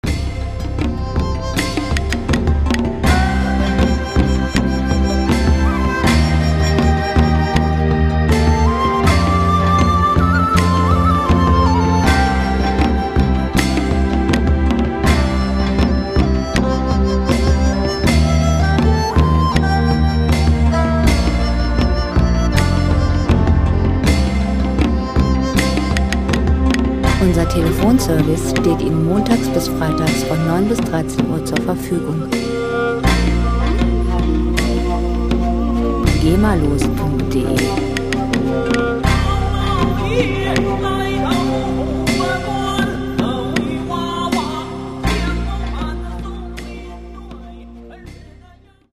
World Music GEMA-frei
Musikstil: Ethno
Tempo: 80 bpm
Tonart: C-Dur
Charakter: zerbrechlich, fein
Instrumentierung: Geige, Flöte, Trommel, Synthie, Gesang